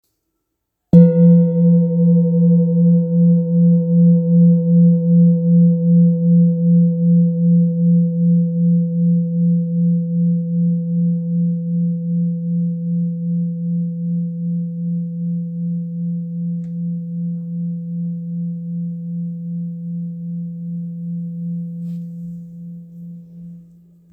Kopre Singing Bowl, Buddhist Hand Beaten, Antique Finishing, Select Accessories, 19 by 19 cm,
Material Seven Bronze Metal
It is accessible both in high tone and low tone .
In any case, it is likewise famous for enduring sounds.